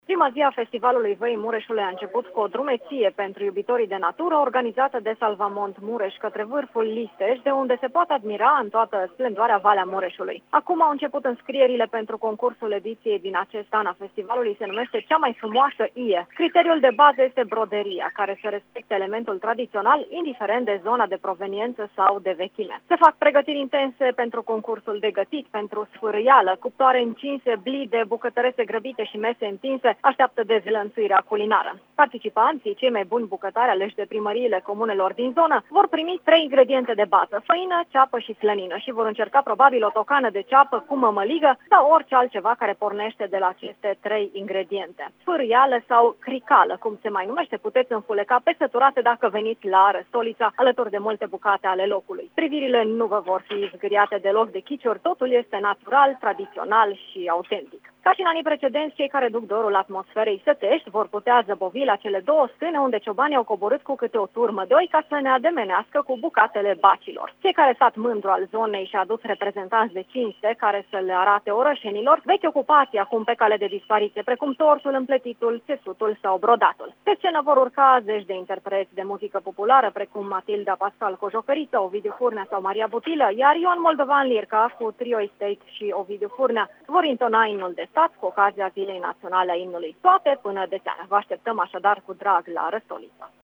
se află la Răstolița și ne vorbește despre atmosfera de la festival: